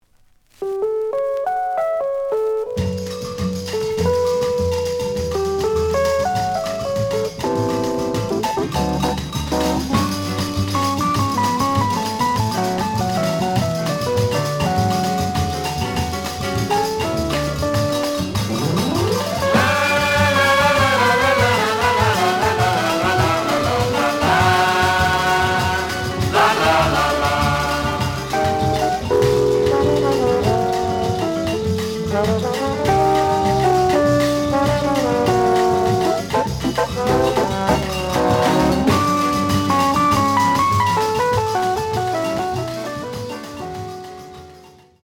The audio sample is recorded from the actual item.
●Genre: Latin Jazz
Slight noise on beginning of A side.